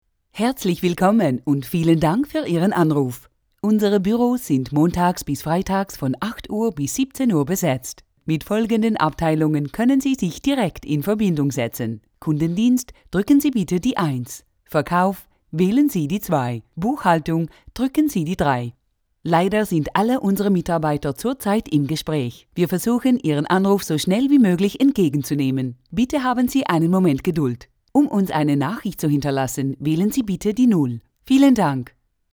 freundlich, ehrlich, authentisch, sinnlich, warm, variabel, seriös, sympatisch, wandlungsfähig, emotional
Sprechprobe: Industrie (Muttersprache):
conversational, friendly, real, soothing, educational, informative, warm